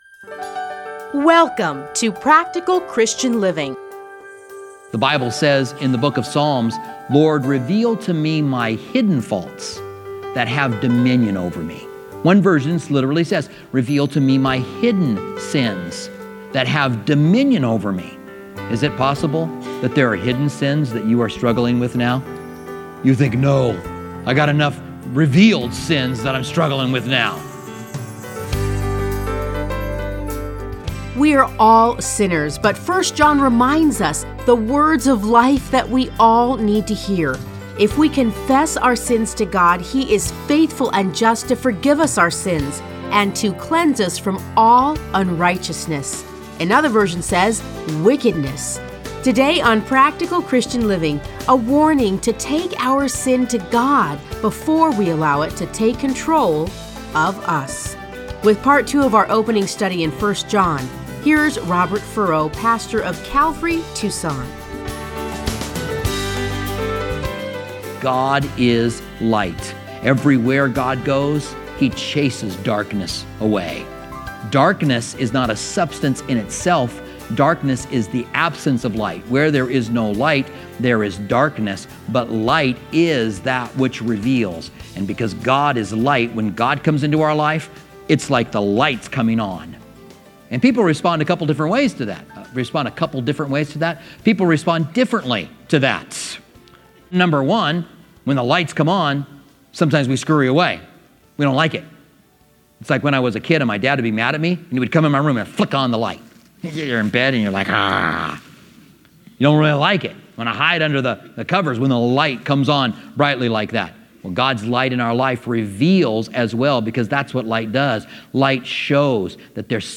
Listen to a teaching from 1 John 1.